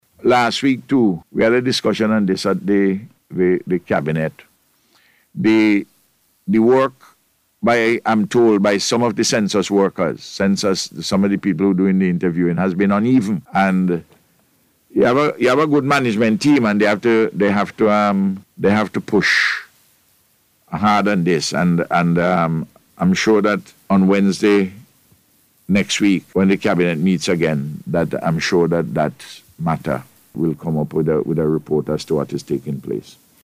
So says Prime Minister Dr Ralph Gonsalves, he was at the time speaking on NBC radio and provided an update on the census’s progress.